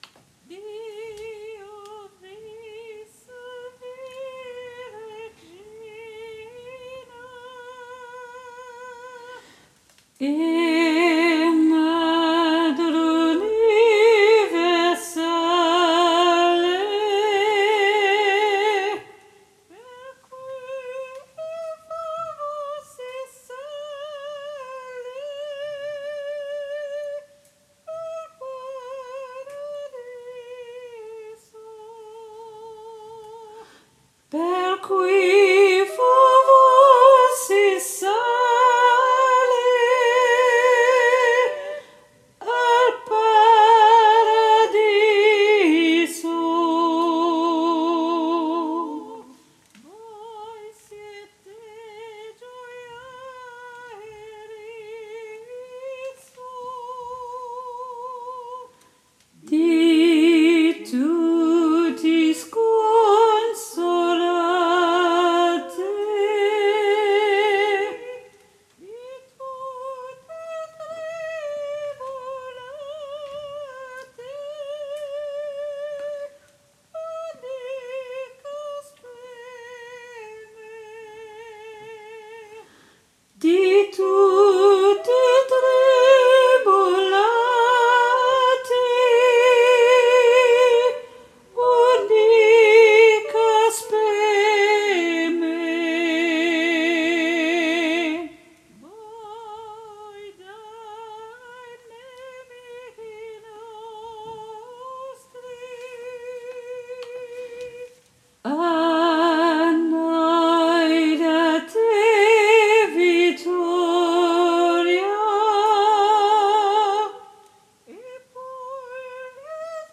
MP3 versions chantées